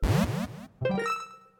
The sound of a Pellet Posy flowering.